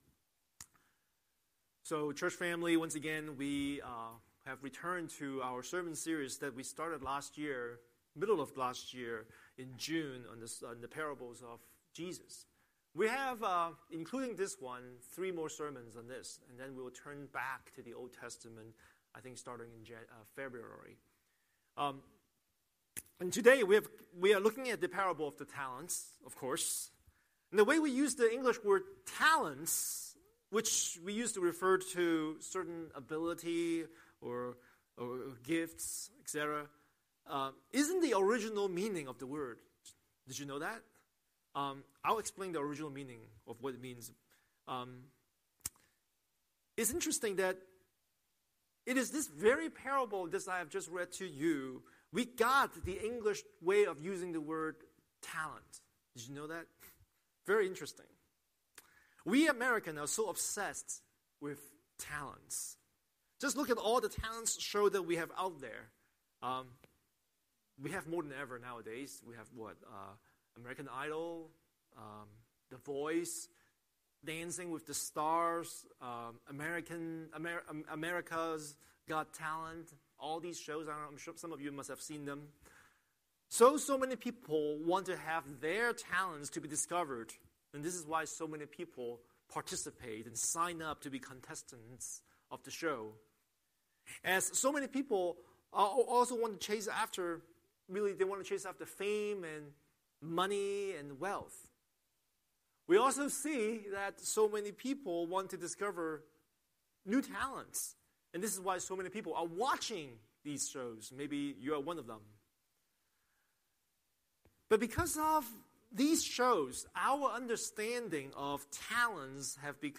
Scripture: Matthew 25:14–30 Series: Sunday Sermon